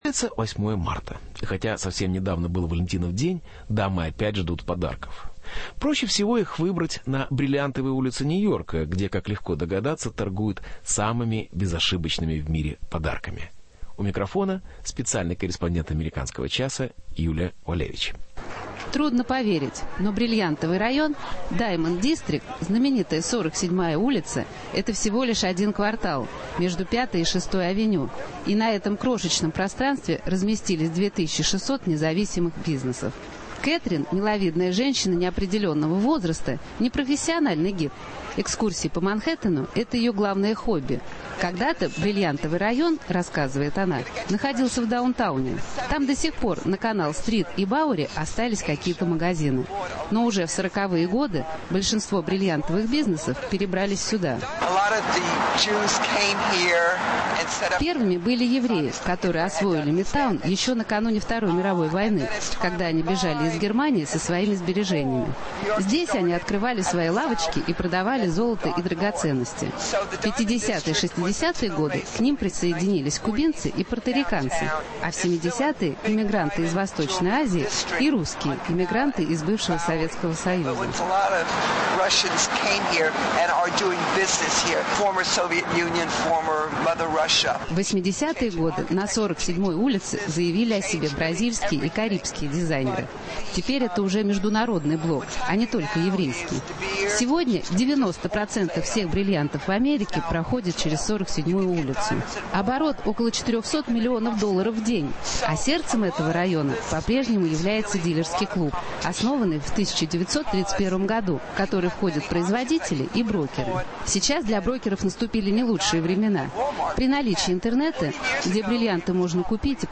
Репортаж с "бриллиантовой" улицы Нью-Йорка